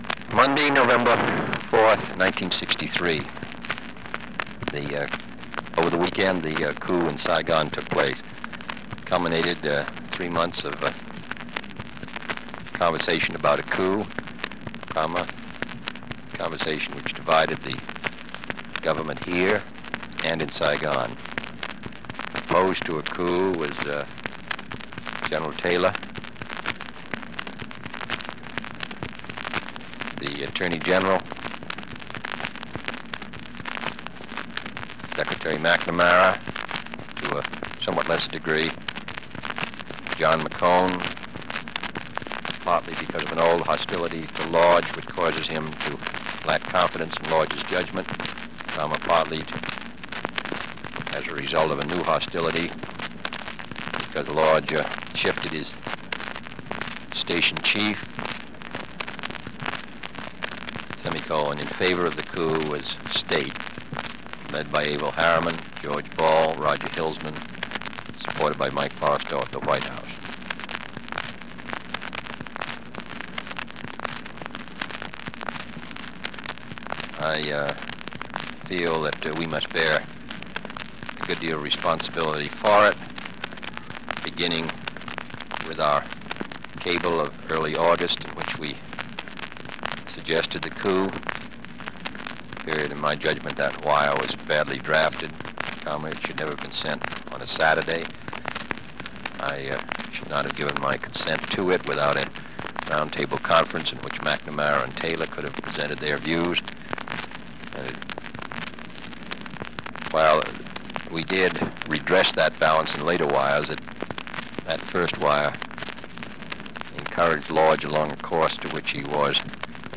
President Kennedy dictated a memorandum giving his version of events. Date: November 4, 1963 Location: Oval Office Tape Number: Dictabelt 52.1 Participants John F. “Jack” Kennedy Associated Resources November 4, 1963 Daily Diary Audio File Transcript